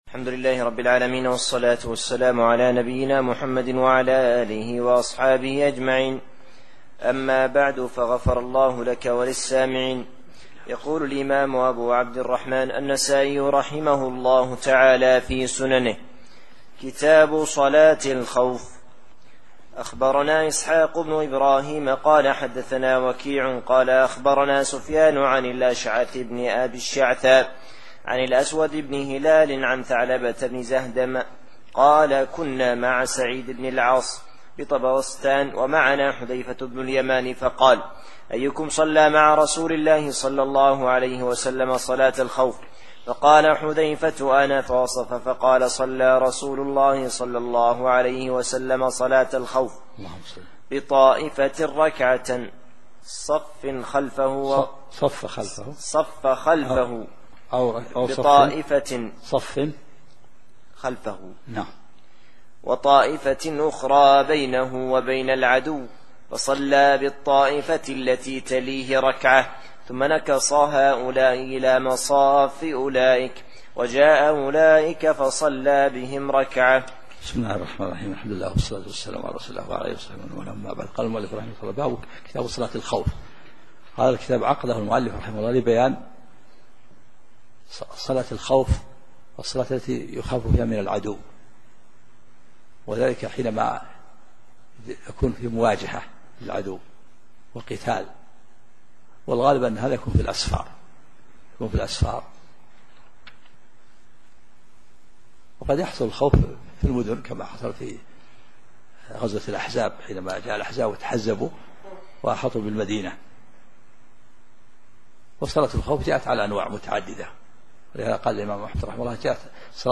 محاضرتان صوتيتان، وفيهما شرح الشيخ عبد العزيز بن عبد الله الراجحي - أثابه الله - لأحاديث كتاب صلاة الخوف من سنن النسائي، للإمام أحمد بن شعيب النسائي - رحمه الله -، والتي تعد من أمهات كتب الحديث الستة، وقد جمع النسائي - رحمه الله - في كتابه أحاديث الأحكام، وقسمه إلى كتب، وقسم كل كتاب إلى أبواب.